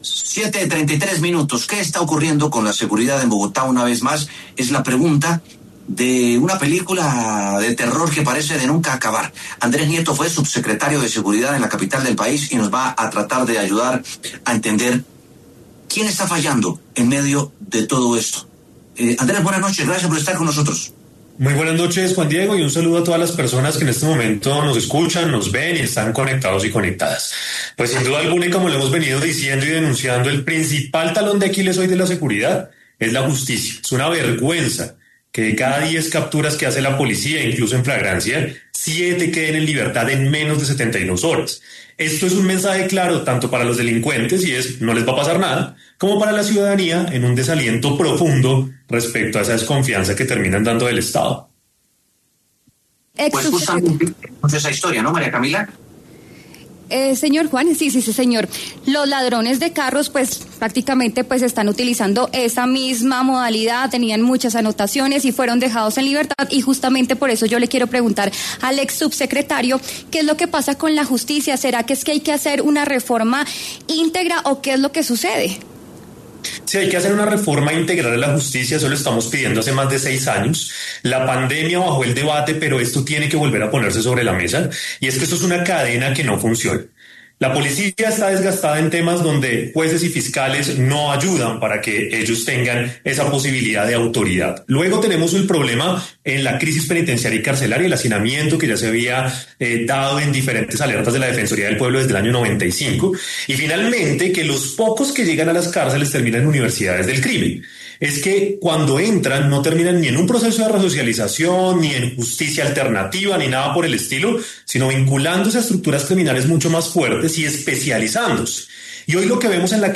Andrés Nieto, quien fue subsecretario de Seguridad de Bogotá, conversó con W Sin Carreta para analizar las nuevas modalidades de delincuencia, no solo en la capital del país, sino en Colombia.